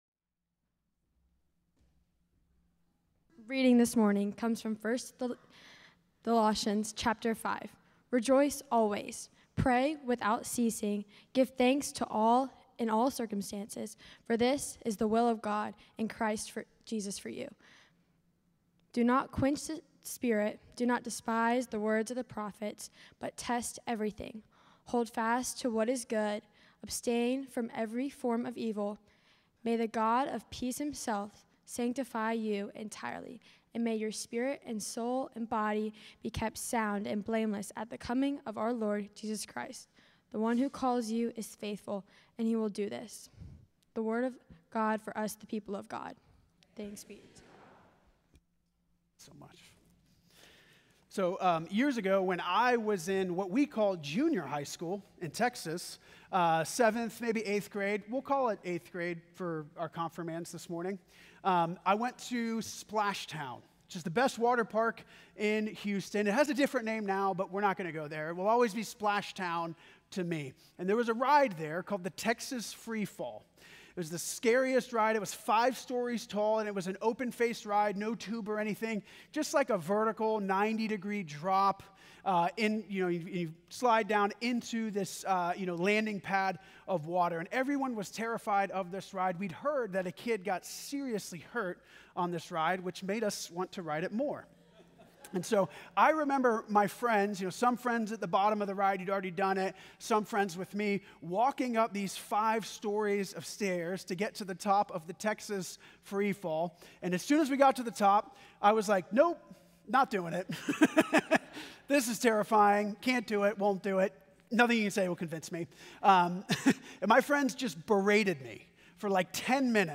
First Cary UMC's First on Chatham Sermon